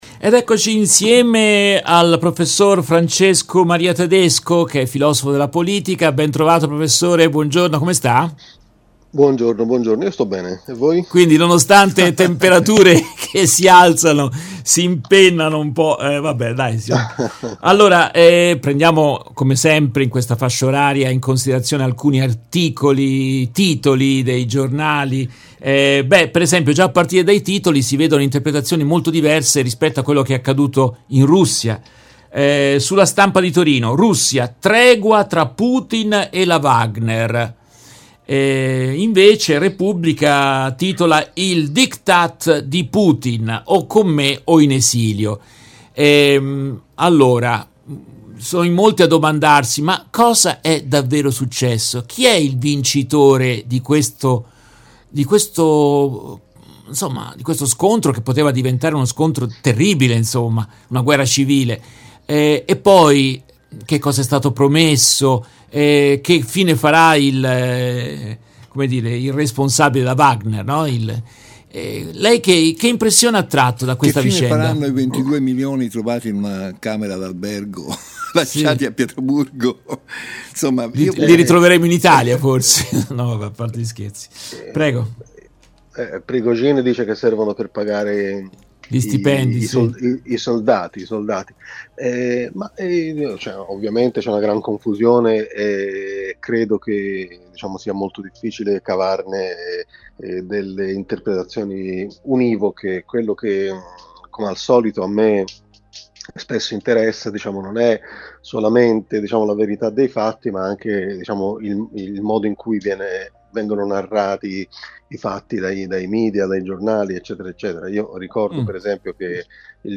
Nell’intervista estratta dalla diretta del 27 giugno 2023